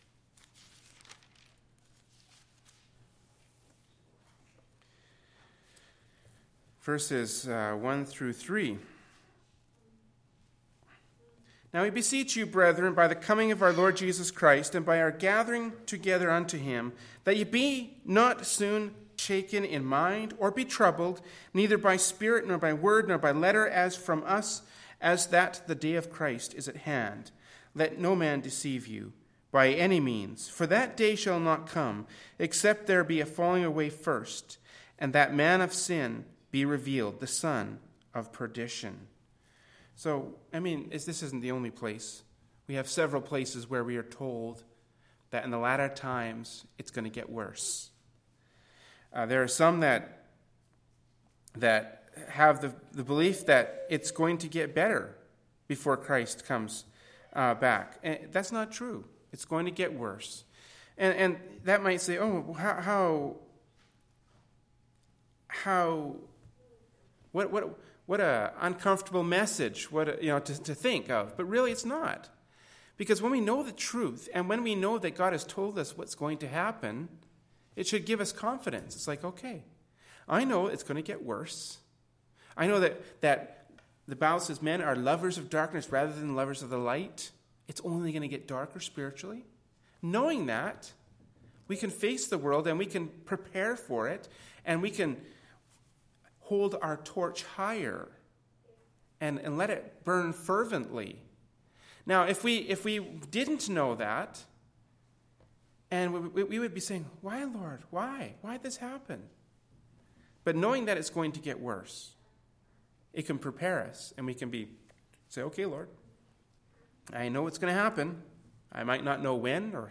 Kamloops, B.C. Canada
Prayer Meeting